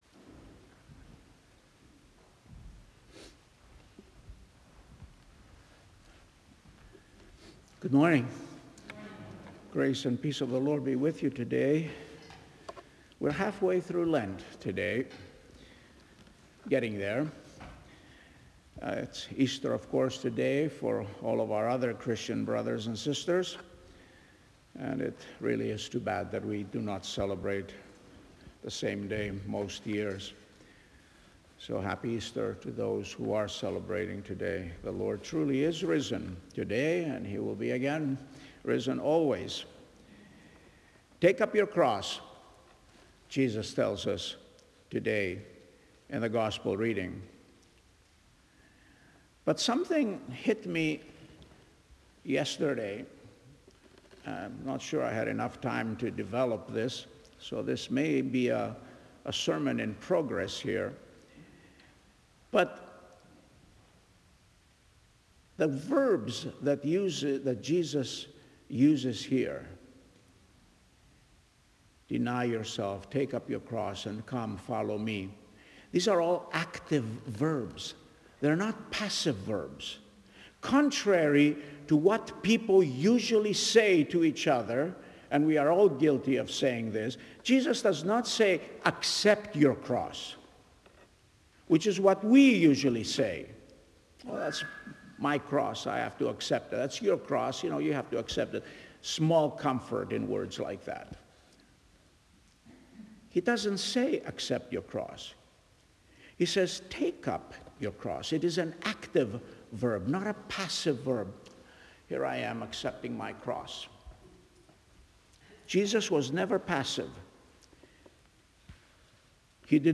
So this sermon is a work in progress.